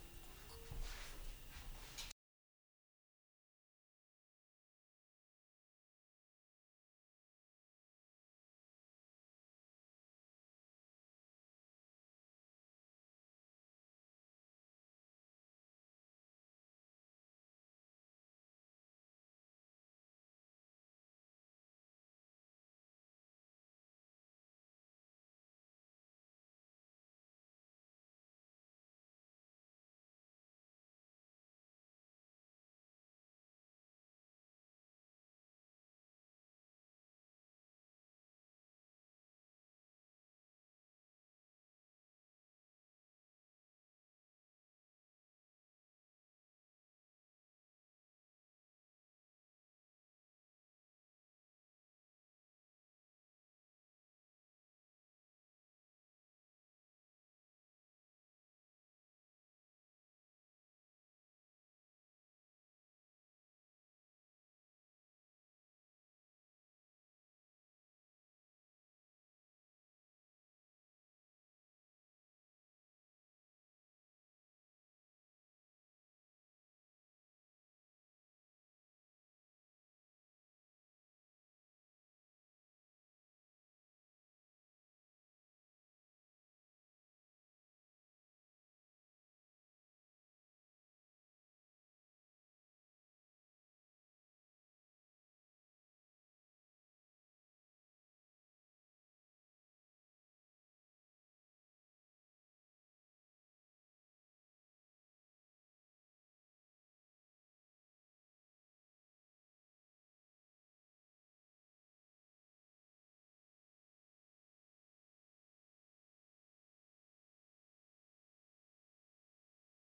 Писк в записи вокала
Здравствуйте! Подскажите пожалуйста, только что записывал песню, изначально все было хорошо, но под конец записи, в дорожках вокала начал появляться какой то писк, причем ни в комнате, ни на улице этого звука не было. Он появился внезапно.